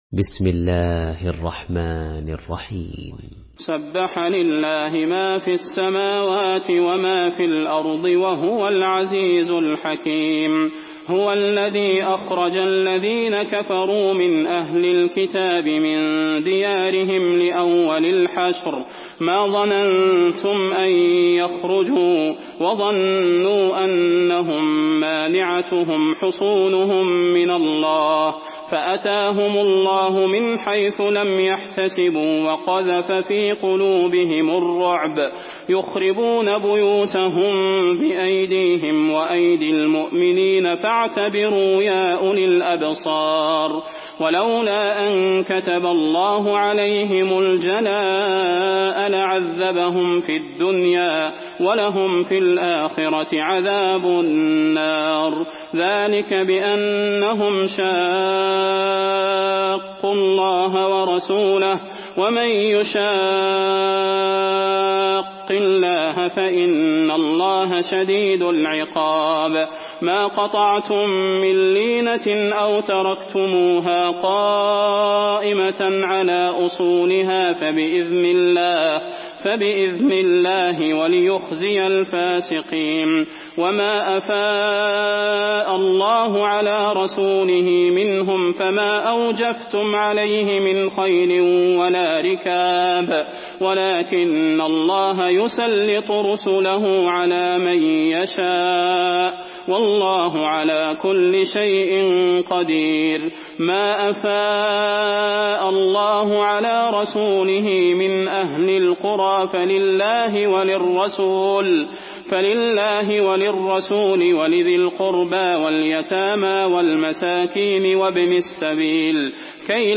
Surah Al-Hashr MP3 in the Voice of Salah Al Budair in Hafs Narration
Murattal Hafs An Asim